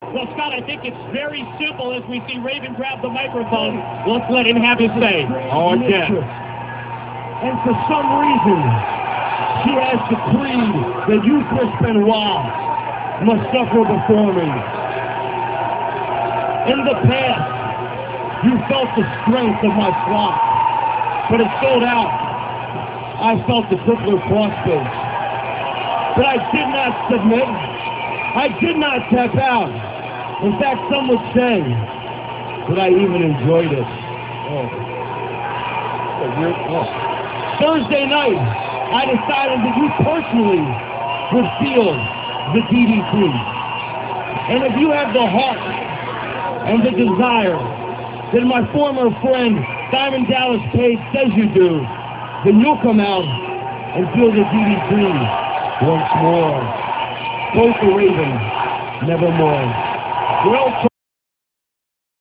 - This speech comes from WCW Saturday Night - [2.7.98]. Raven talks about how fate has decreed that Chris Benoit must suffer before him.